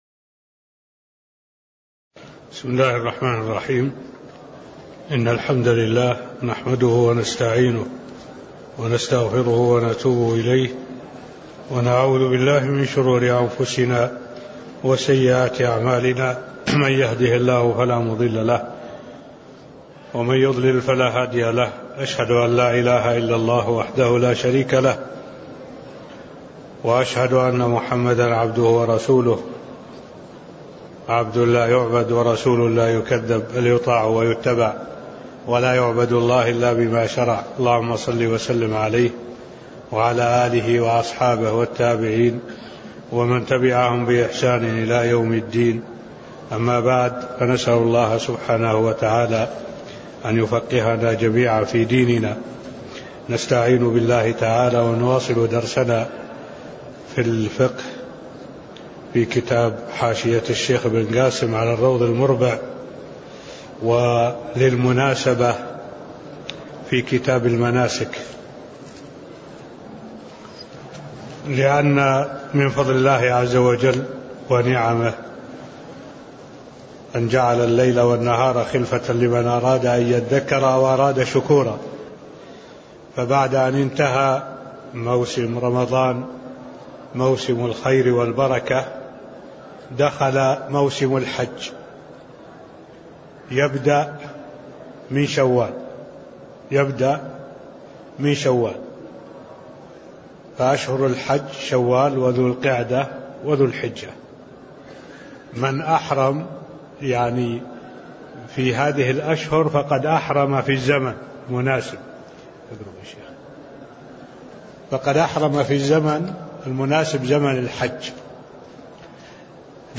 تاريخ النشر ٢٠ شوال ١٤٢٨ هـ المكان: المسجد النبوي الشيخ: معالي الشيخ الدكتور صالح بن عبد الله العبود معالي الشيخ الدكتور صالح بن عبد الله العبود مقدمة (001)